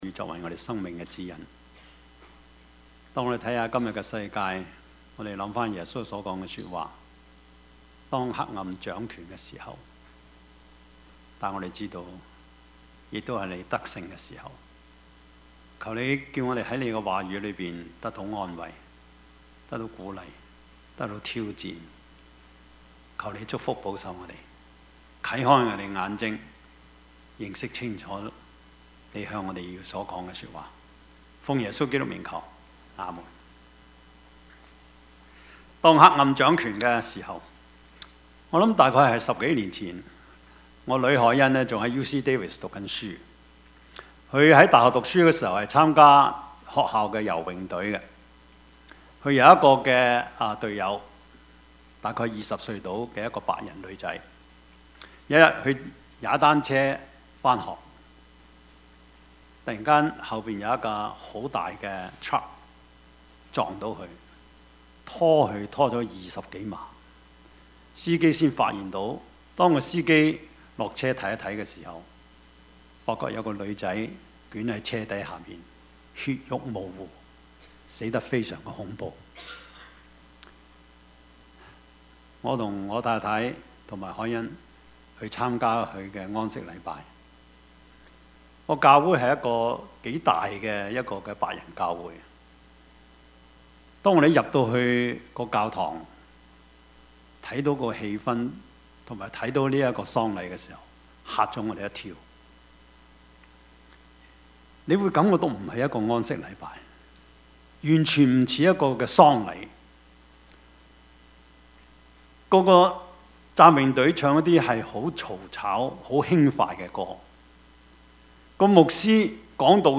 Service Type: 主日崇拜
Topics: 主日證道 « 男人大丈夫 英雄？